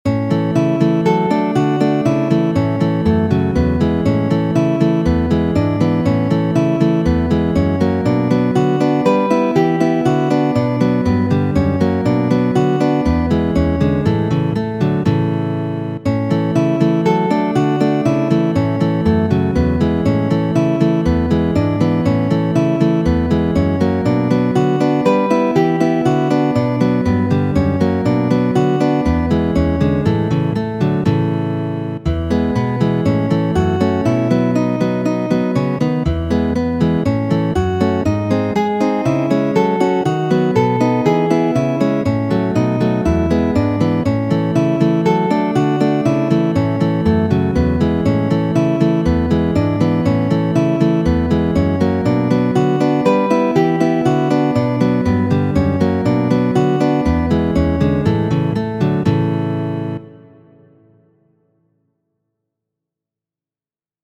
marŝo verkita de Fransisko Tarego, kaj publikigita kiel Studo 23-a,!